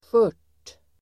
Uttal: [sjör_t:]